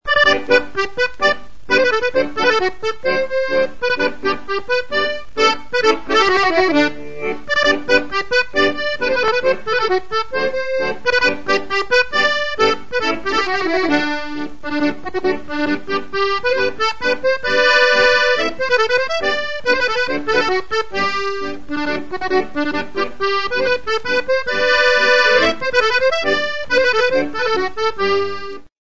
Instruments de musique: AKORDEOIA; SOINUA